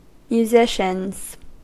Ääntäminen
Ääntäminen US : IPA : [mjuːˈzɪ.ʃənz] Haettu sana löytyi näillä lähdekielillä: englanti Käännöksiä ei löytynyt valitulle kohdekielelle. Musicians on sanan musician monikko.